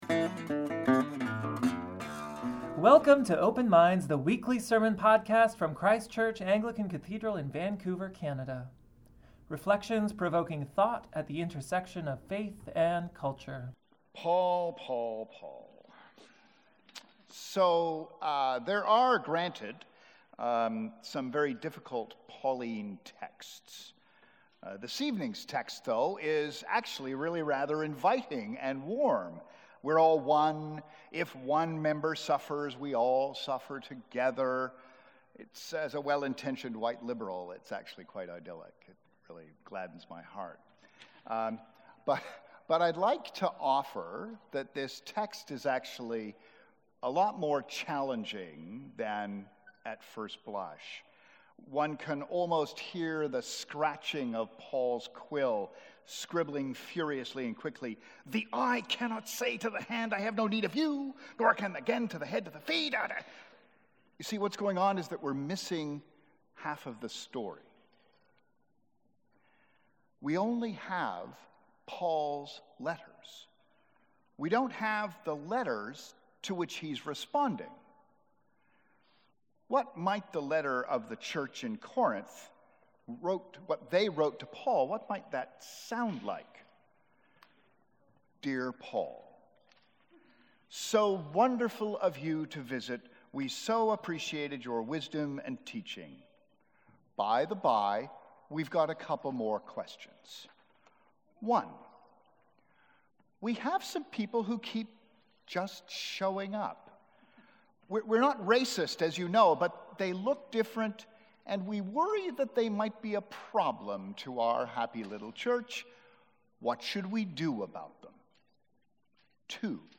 Sermons | Christ Church Cathedral Vancouver BC